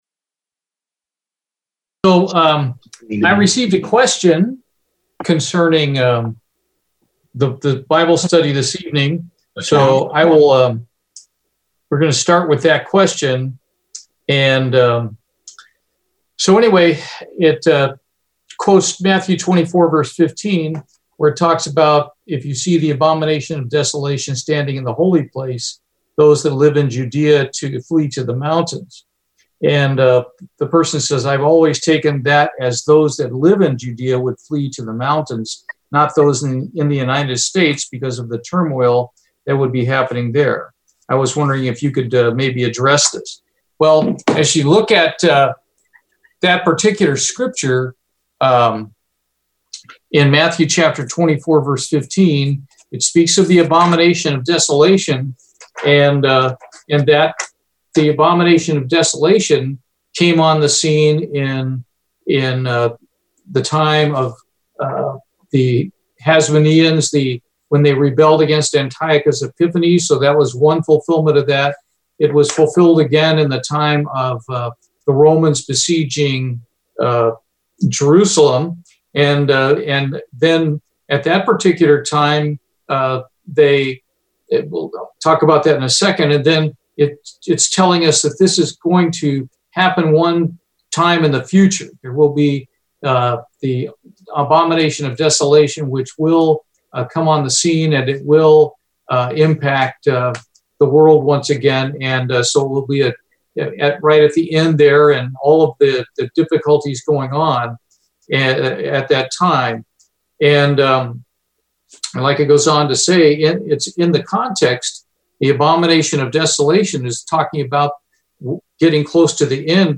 This Bible study concludes the two part series on the place of safety.